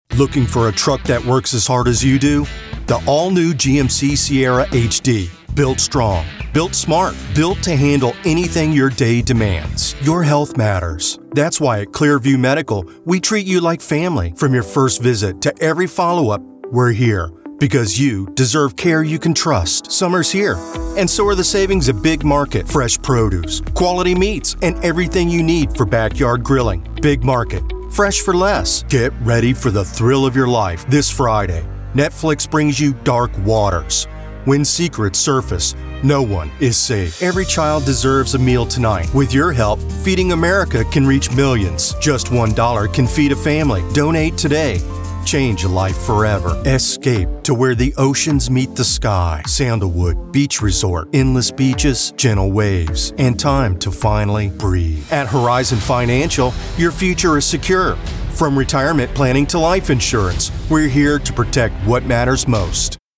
Powerful, rich voiceovers for commercials, audiobooks, and narration.
Commercial-Demo.mp3